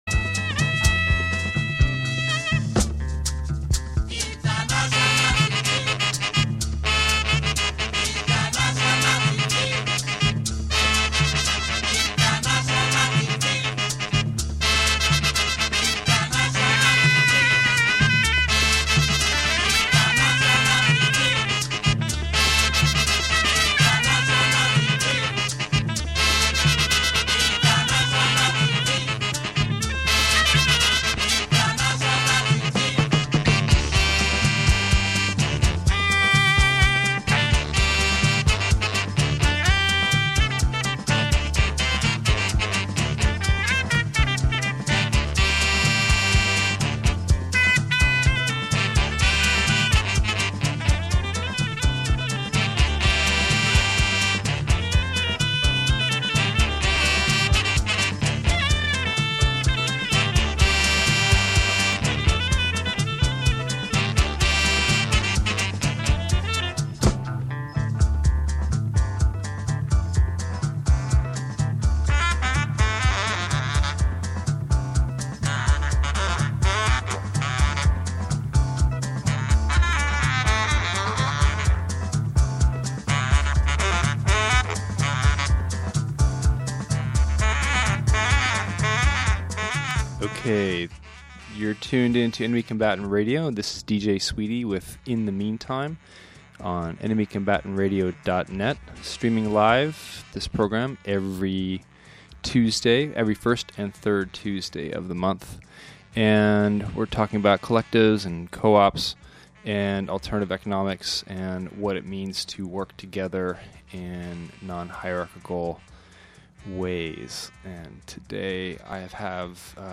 Interview on March 1